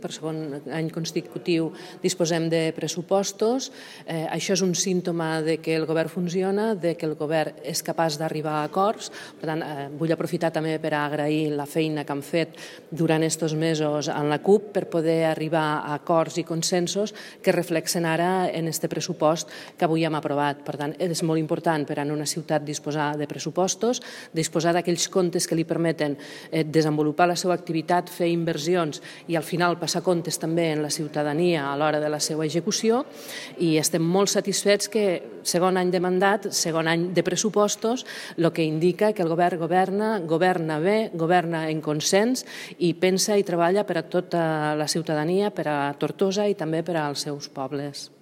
El Govern de Movem-PSC i ERC ha aprovat amb el suport de la CUP el pressupost per al 2025, en un ple extraordinari celebrat aquest divendres.